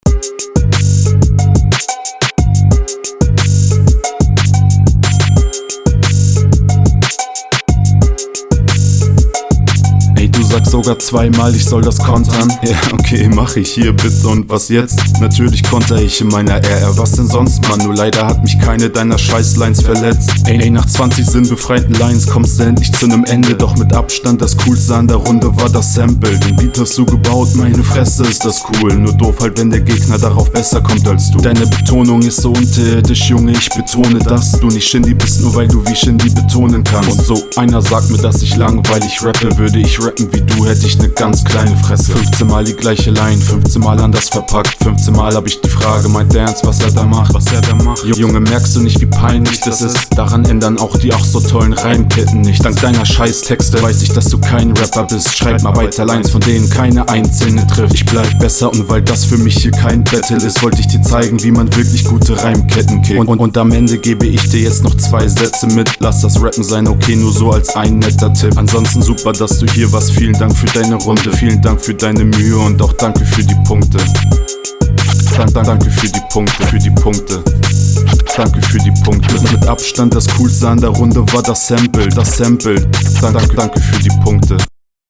find ich cooler, die scratches am ende sind aber bisschen peinlo; deine reimkette ist leider …
rappst tatsächlich für meinen geschmack auch besser auf diesem beat auch wenn du immer noch …